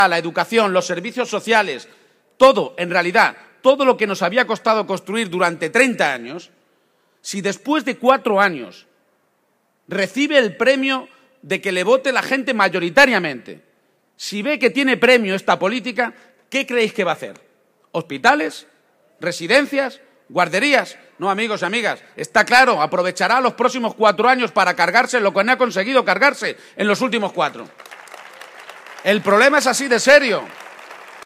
El candidato socialista a la Presidencia de Castilla-La Mancha protagonizo el mitin de fin de campaña en Albacete y anuncio que promoverá un código ético público que obligue a los partidos a presentar programa